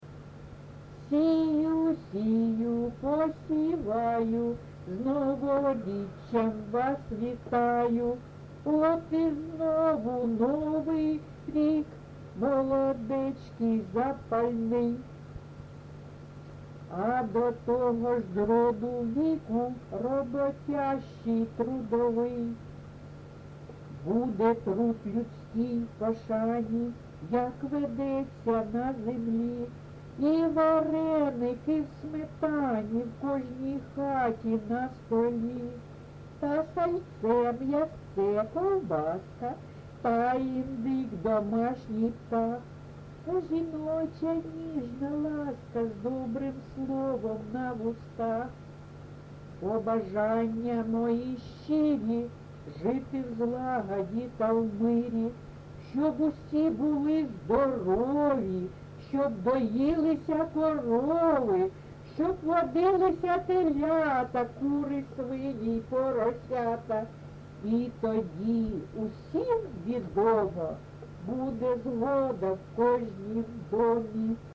ЖанрЩедрівки, Сучасні пісні та новотвори
Місце записус. Чорнухине, Алчевський район, Луганська обл., Україна, Слобожанщина